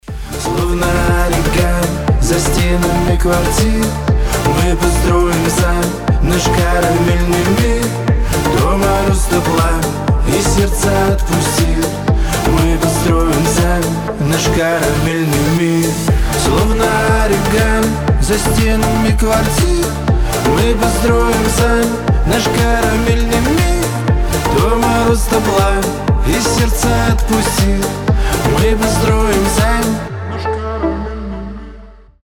поп , танцевальные